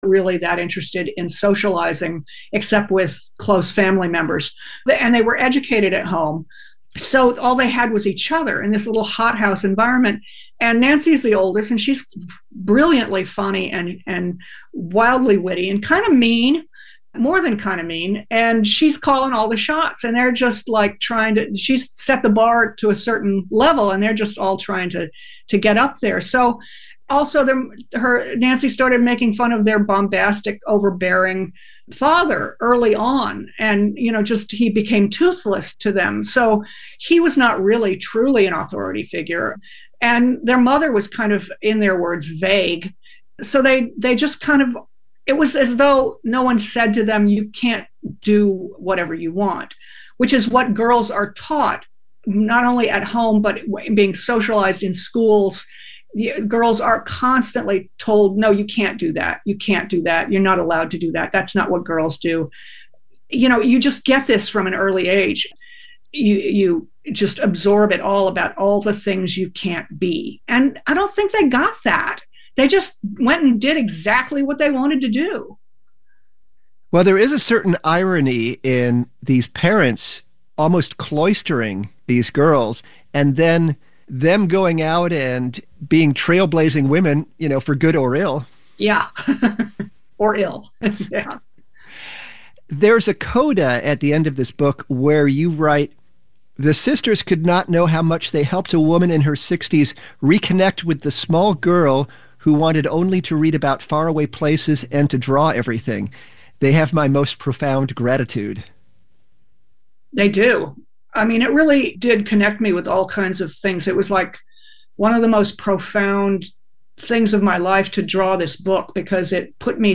Conversations with trailblazers in all the narrative arts - film, stagecraft, comics, and animation